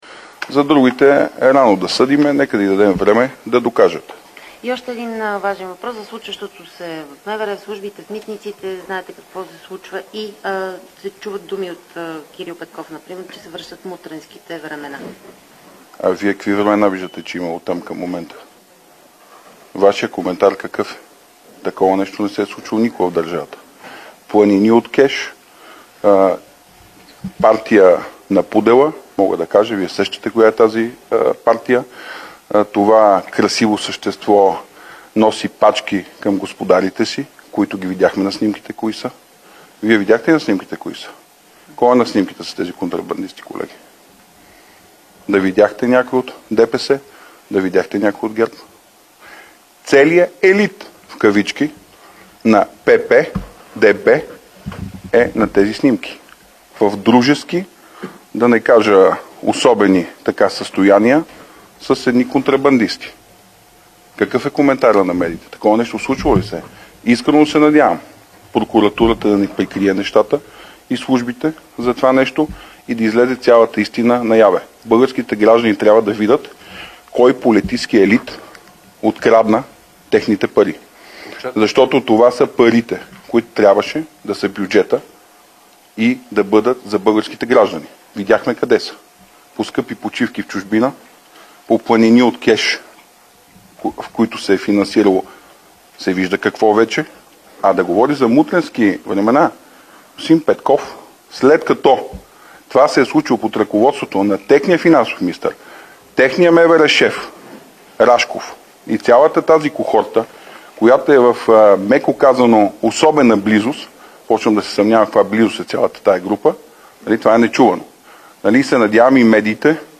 10.10Брифинг на Цончо Ганев от „Възраждане"  за служебния кабинет.
Директно от мястото на събитието